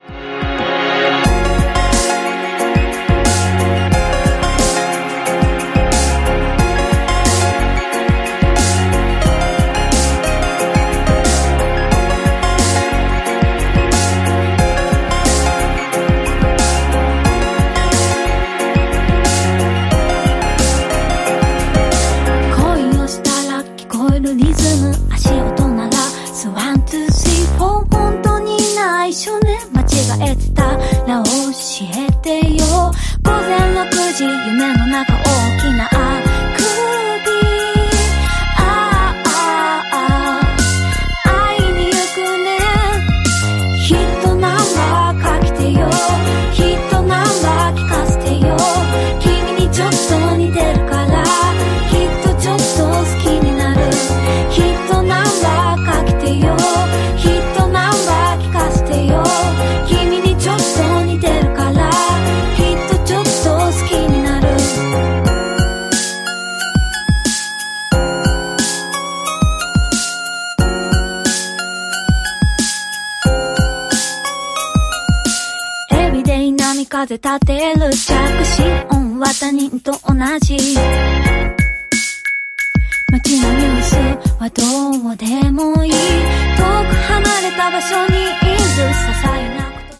# POP# CLUB